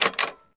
Re Typewriter Sound Button - Free Download & Play
Sound Effects Soundboard2 views